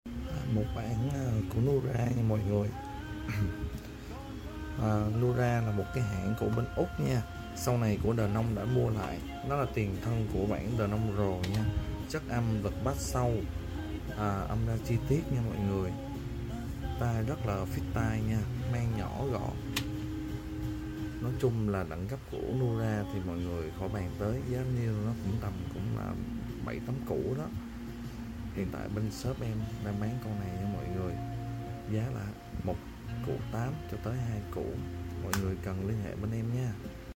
âm basa sâu hay , âm nhẹ chi tiết